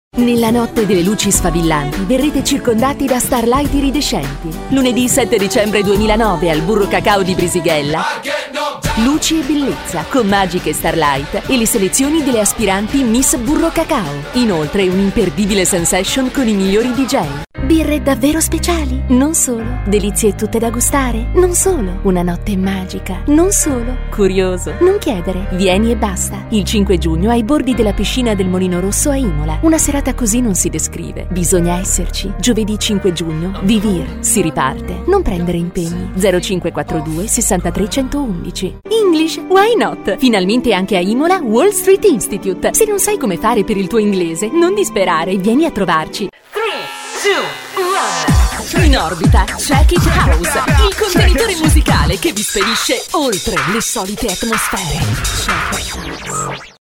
Mother-tongue Italian professional Voiceover Talent.
Sprechprobe: Industrie (Muttersprache):